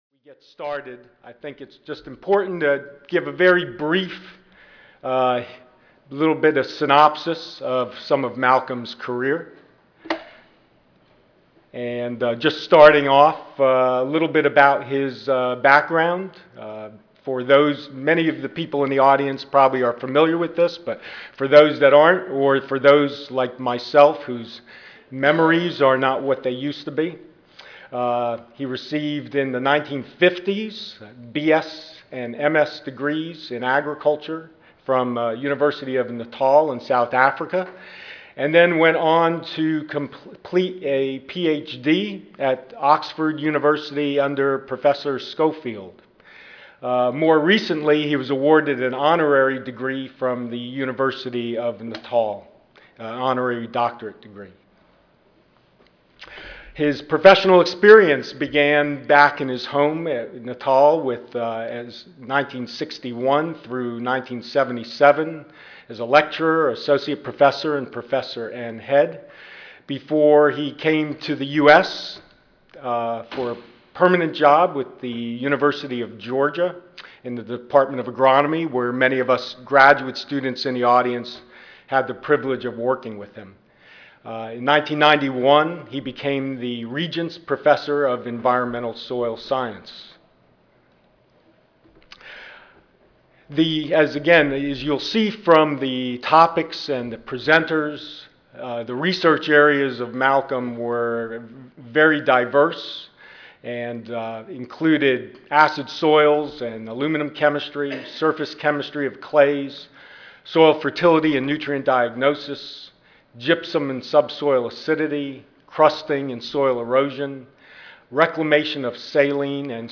Oral Session
Audio File Recorded presentation